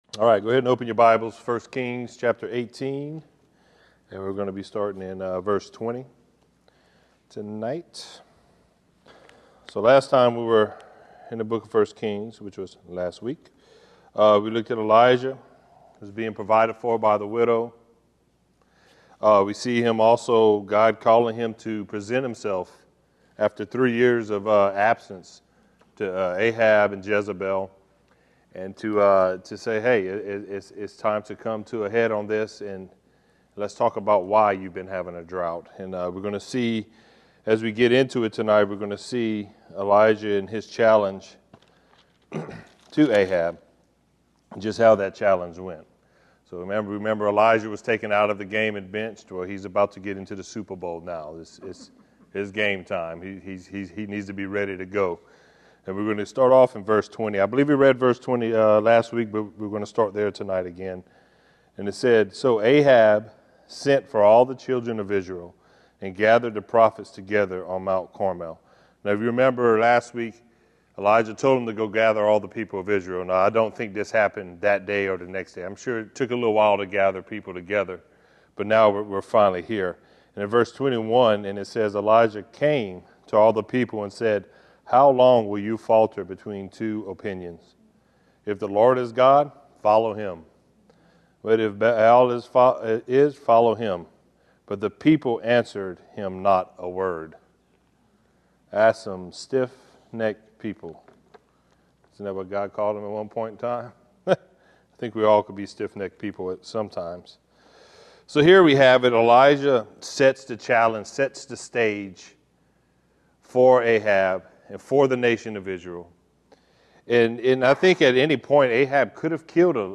The book of 1 Kings is the sequel to 1 and 2 Samuel and begins by tracing Solomon’s rise to kingship after the death of David. The story begins with a united kingdom, but ends in a nation divided into 2 kingdoms. Join us for this verse by verse study